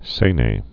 (sānā)